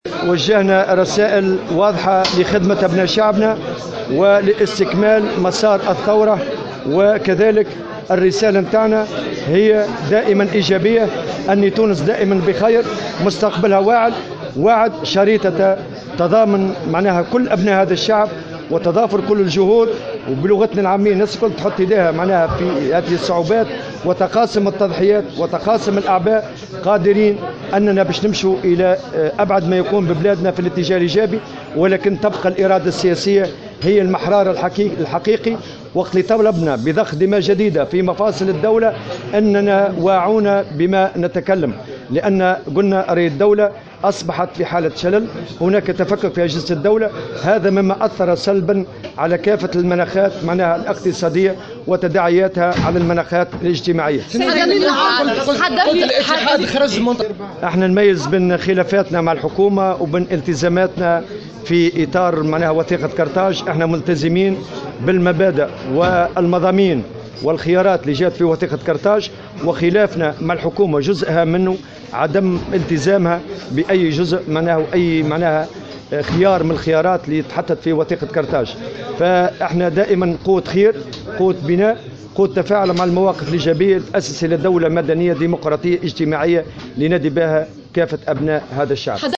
وأوضح أن هذه العوامل أثّرت سلبا على الأوضاع الاقتصادية والاجتماعية، وذلك في تصريحات لمراسلة "الجوهرة اف ام"، على هامش تجمّع نقابي في تونس العاصمة بمناسبة الاحتفال بعيد العمال العالمي.